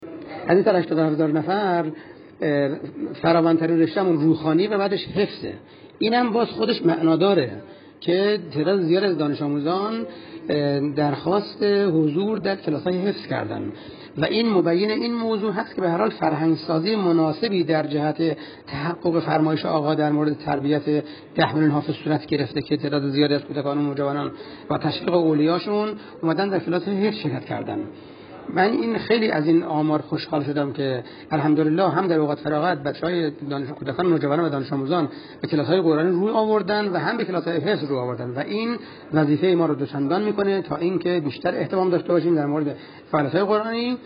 میکائیل باقری، مدیرکل قرآن، عترت و نماز وزارت آموزش و پرورش در گفت‌وگو با ایکنا با اشاره به فعالیت پایگاه‌های اوقات فراغت دانش‌آموزی با رشته‌های مختلف قرآنی، فرهنگی، ورزشی، مهدویت و ...، اظهار کرد: در کشور حدود 30 هزار پایگاه اوقات فراغت برای غنی‌سازی اوقات فراغت دانش‌آموزان با رشته‌های مختلف فعال است.